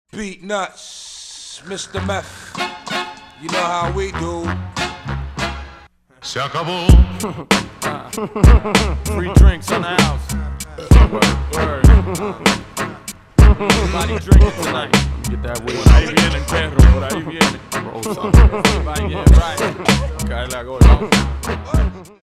• Качество: 320, Stereo
мужской голос
спокойные
ремиксы
Популярный рэп мотив из Тик Тока